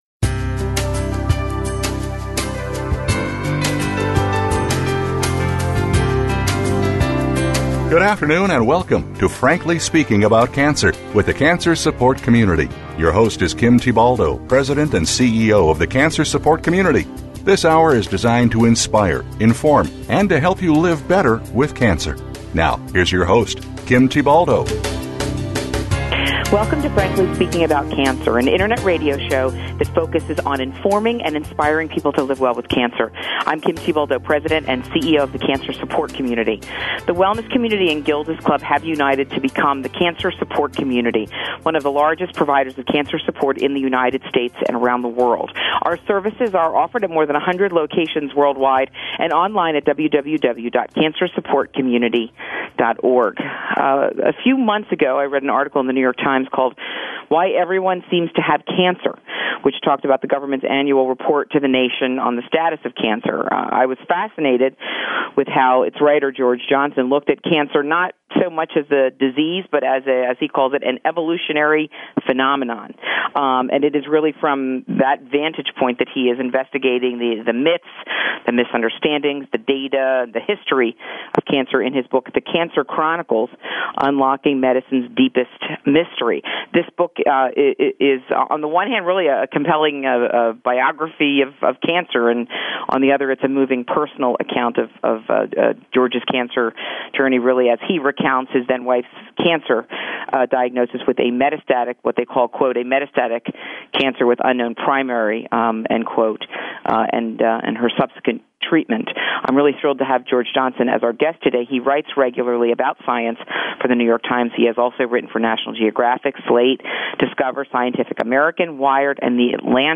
What do we really know about cancer? Joining the show is author George Johnson, who has investigated the myths, misunderstandings